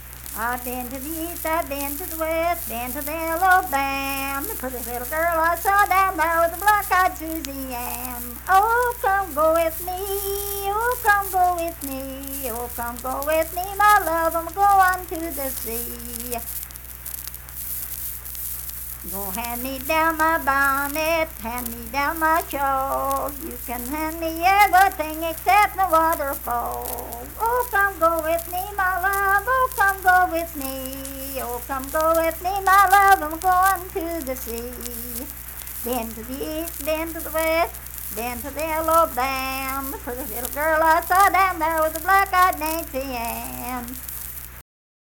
Unaccompanied vocal music performance
Verse-refrain 3(4) & R(4).Born February 9, 1874 in Wayne County, West Virginia.
Minstrel, Blackface, and African-American Songs
Voice (sung)